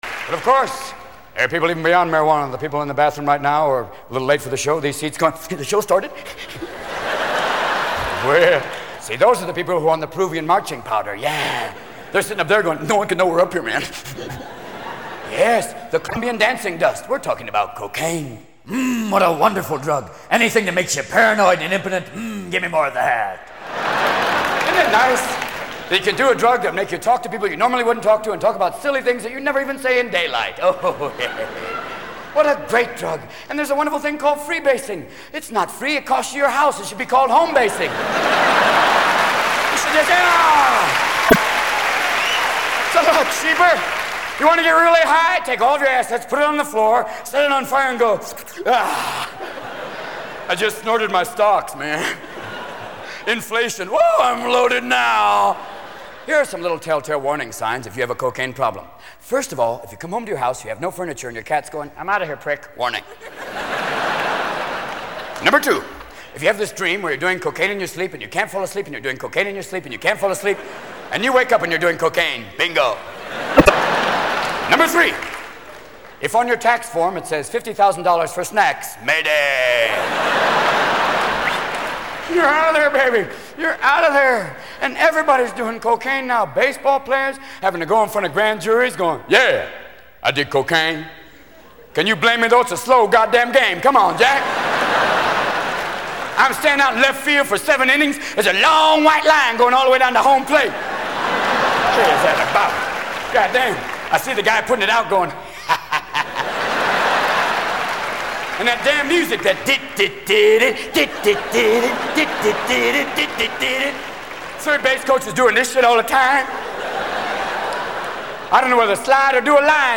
Tags: Funny Comedy Rock Music Cool adult